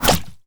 bullet_impact_mud_07.wav